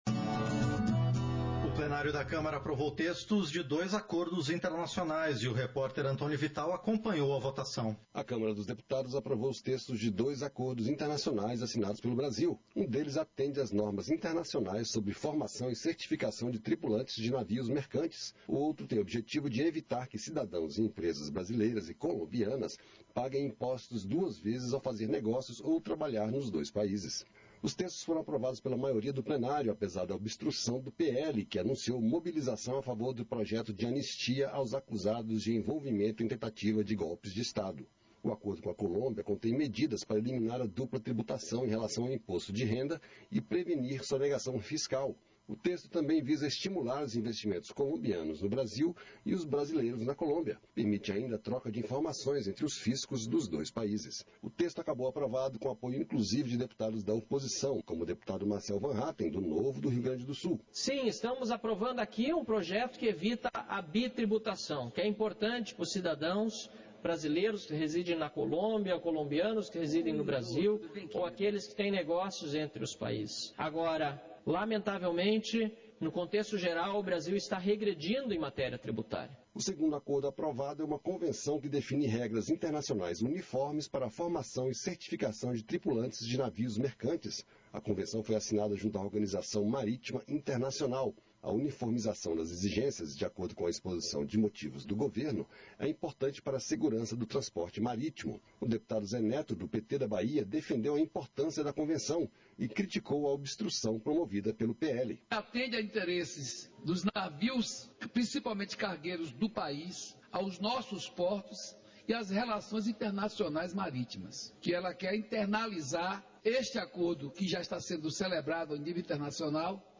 Sessões Plenárias 2025